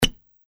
球鞋摩擦木板地面的高频－YS070525.mp3
通用动作/01人物/01移动状态/球鞋摩擦木板地面的高频－YS070525.mp3